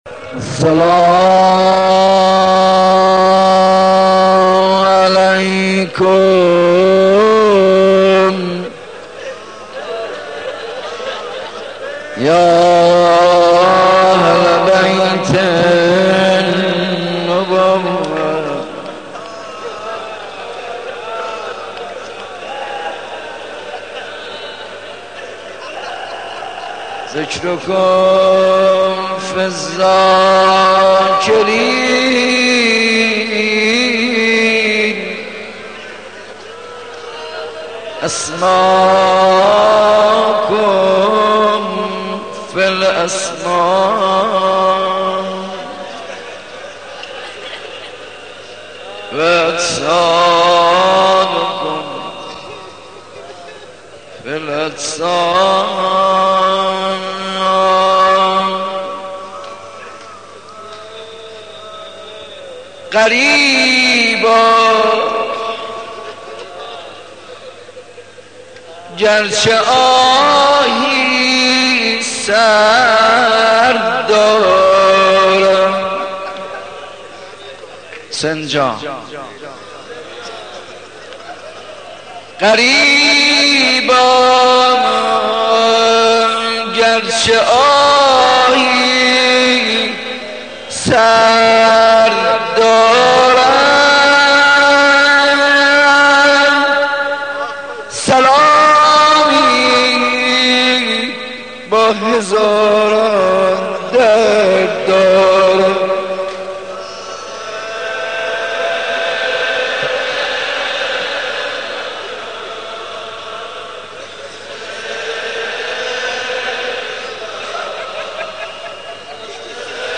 shabe 03 moharram 78 ark.mp3